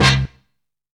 OLDSTYLE HIT.wav